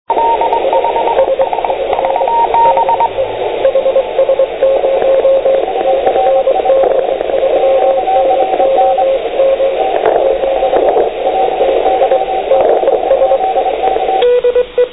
Tak tato nahrávka pochází z roku 2003. Tato stanice volala výzvu v pásmu 80m.
Všimněte si charakteru signálu, který jakoby "vystřeluje".
Tato nahrávka pocházela z doby, kdy v HS zrovna vycházelo sluníčko.